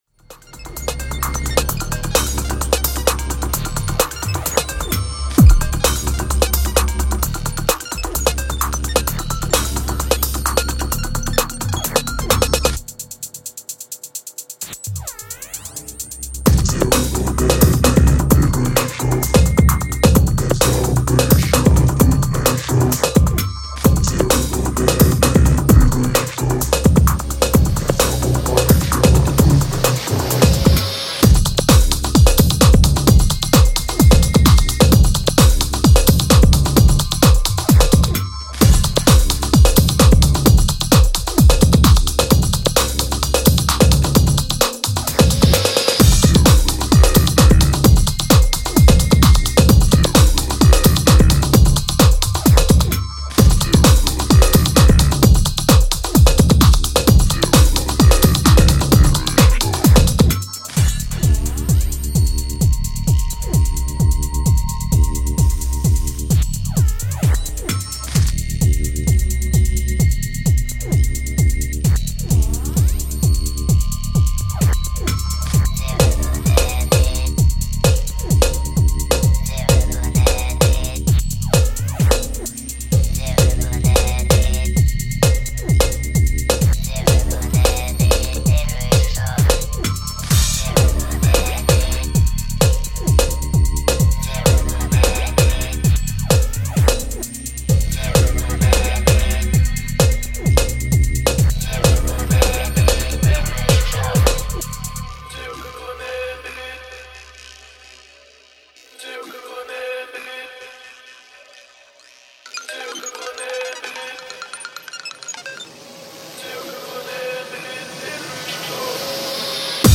Acid , Breaks , Techno , Trance , Tribal